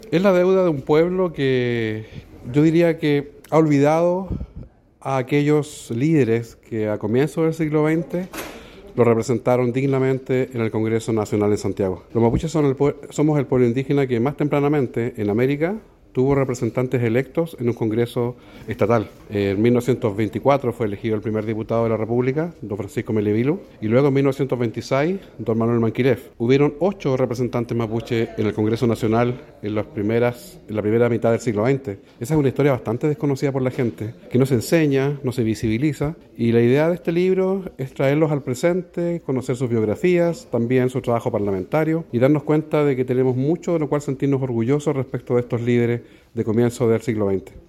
En la Biblioteca Pública de Castro se realizó la presentación del libro “La senda de los lonkos” del escritor y periodista mapuche Pedro Cayuqueo, instancia centrada en la recuperación de la memoria política del pueblo mapuche.